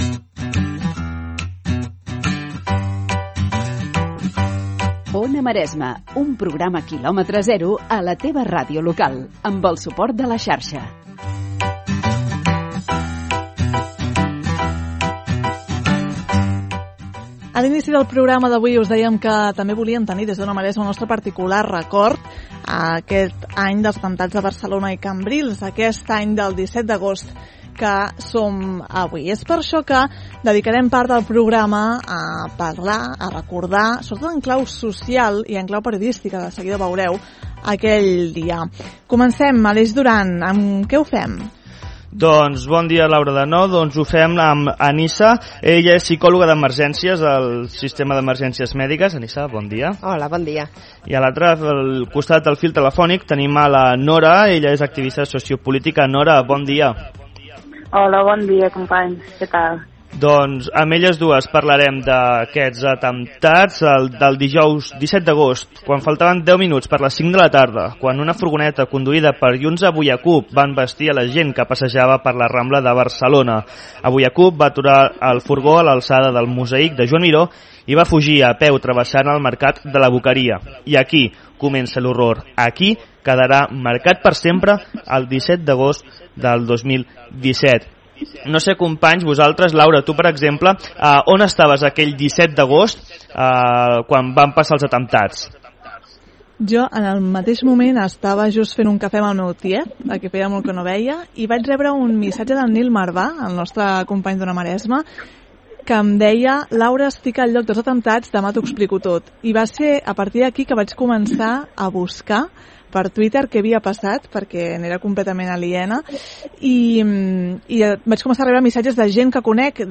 En una tertúlia al programa comarcal Ona Maresme
TERTÚLIA-17A-Un-any-dels-atemptats.mp3